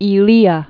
Zeno of E·le·a
(ē-lēə) 495?-430?